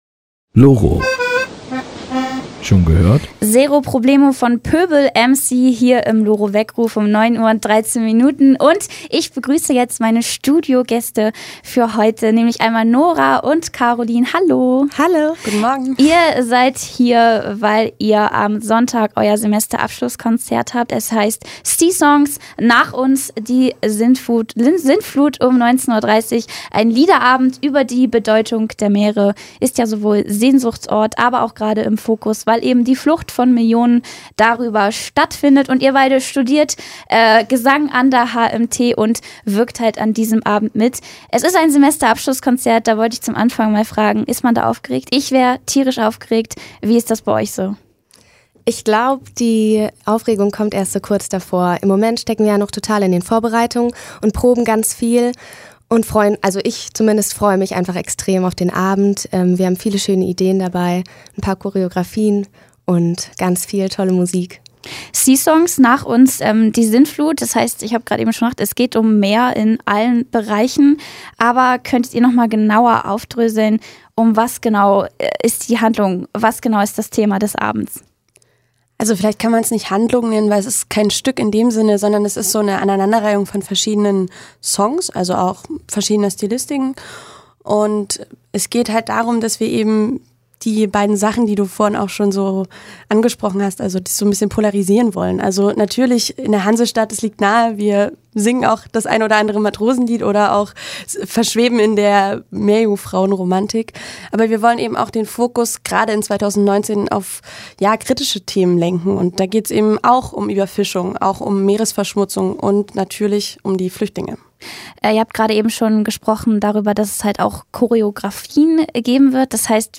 Radio zum Nachhören